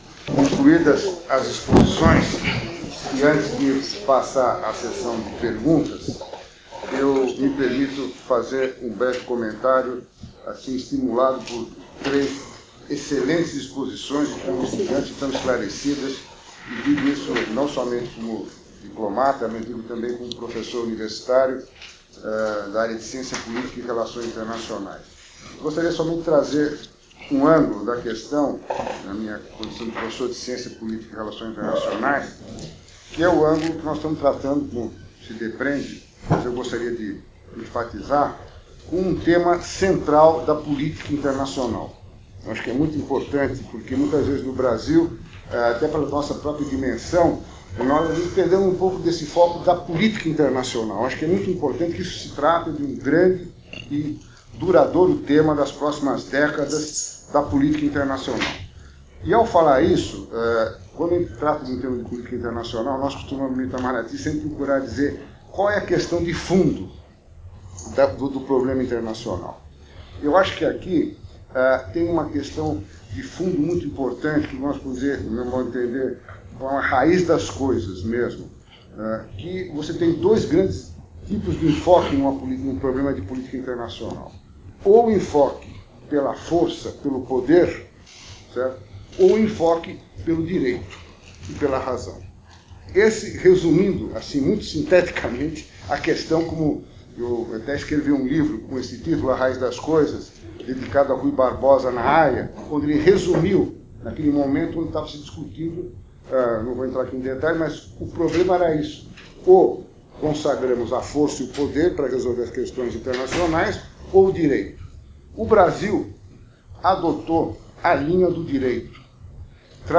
Apresentação e Áudio dos debates na Mesa
consegi2013debate.mp3